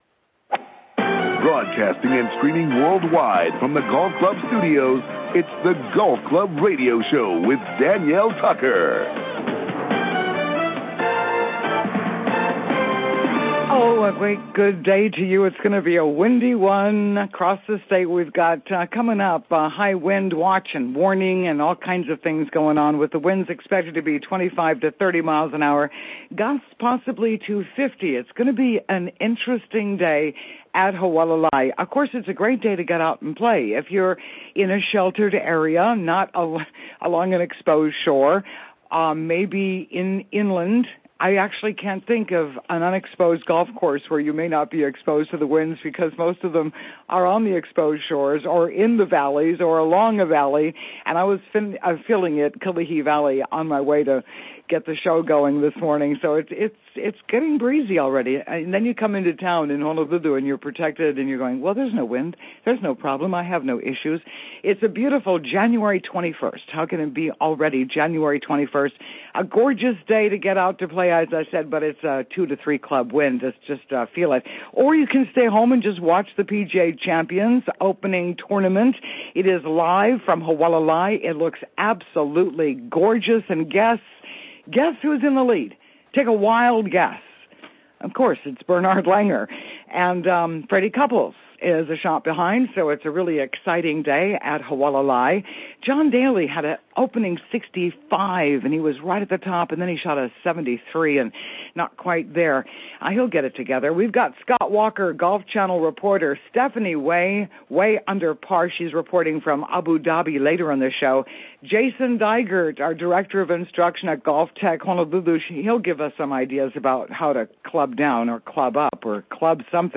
SATURDAY MORNINGS: 7:00 AM - 8:30 AM HST MAUI OAHU KAUAI HILO KONI FM 104.7 KGU FM 99.5 KTOH FM 99.9 KPUA AM 670